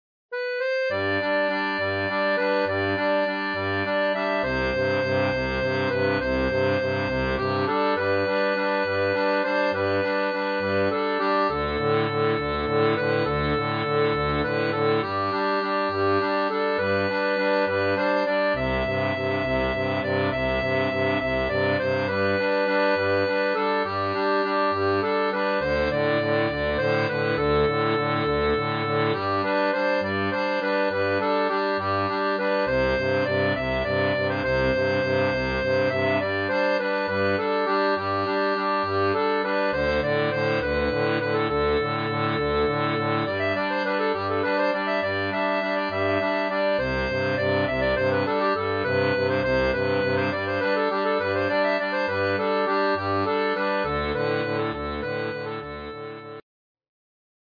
Type d'accordéon
Chanson française